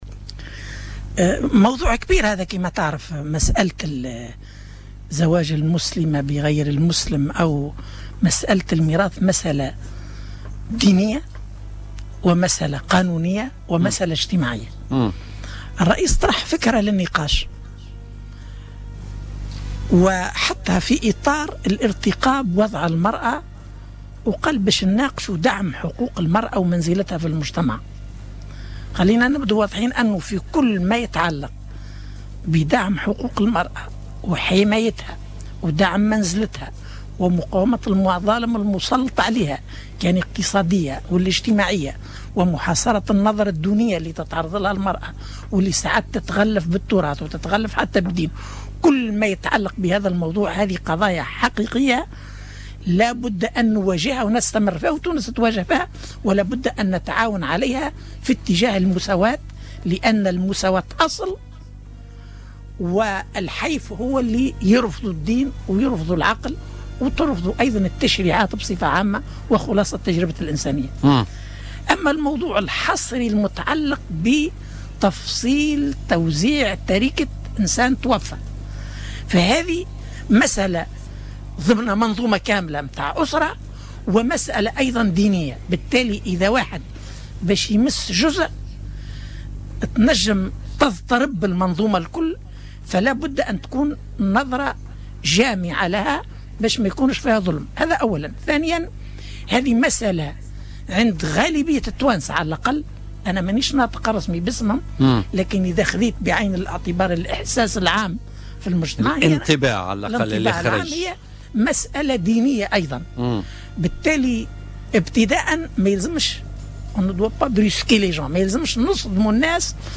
وأضاف ضيف "بوليتيكا" على "الجوهرة أف أم" اليوم الخميس أن الدستور التونسي الجديد يقتضي مراجعة عدد كبير من القوانين تتعلق بالحقوق الفردية.